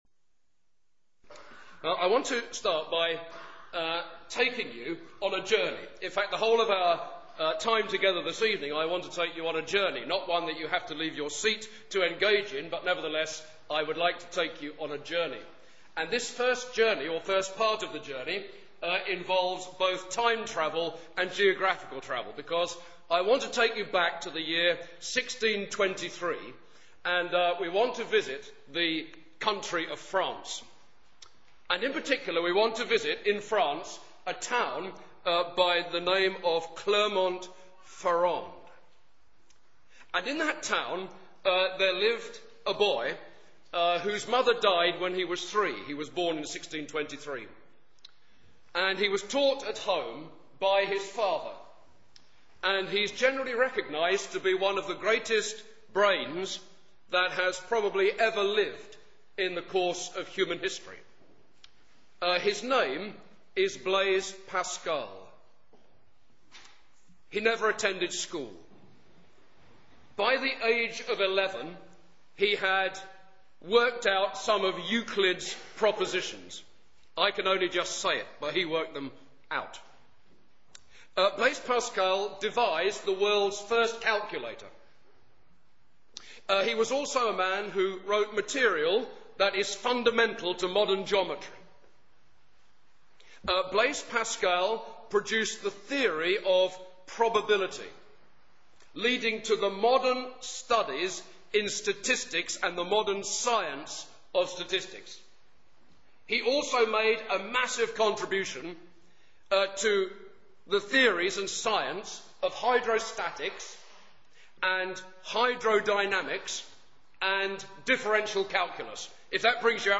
(Message given at the Town Hall, Towcester, 2006)